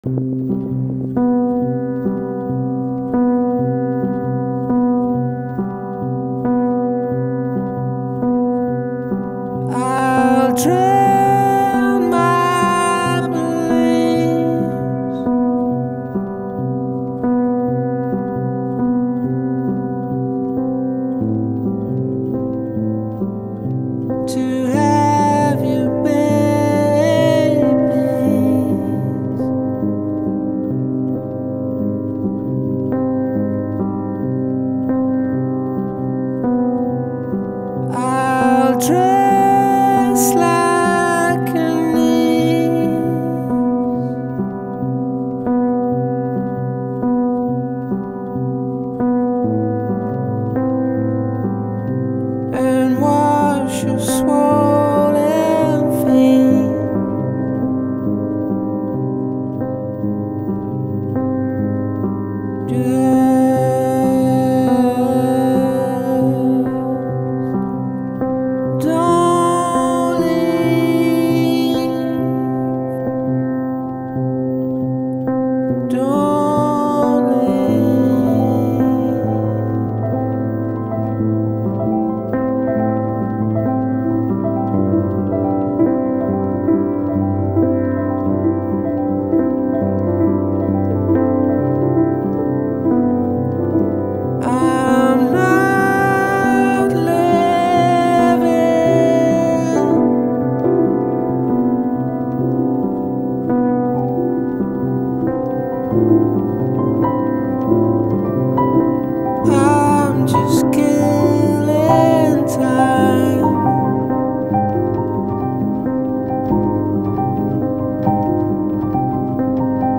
غمگین ترین آهنگ این گروه است.